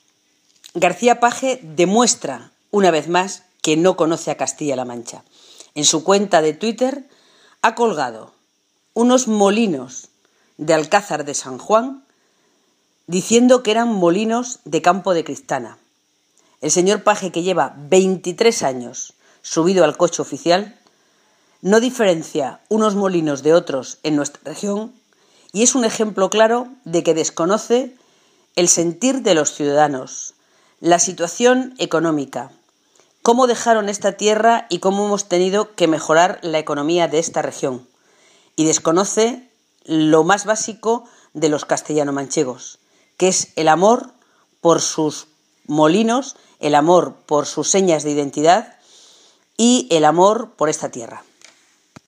Corte_de_voz_de_Carmen_Riolobos.mp3